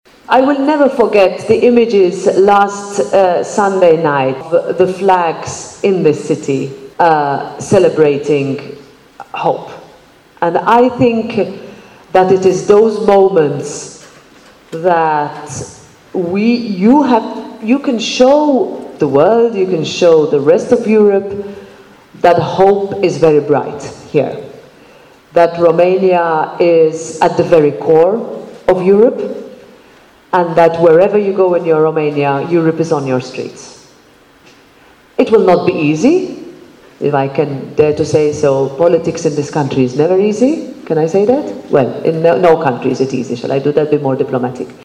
Sute de tineri din Timișoara, dar și alte orașe ale țării s-au întâlnit astăzi la Sala “Capitol”, cu președinta Parlamentului European, Roberta, Metsola.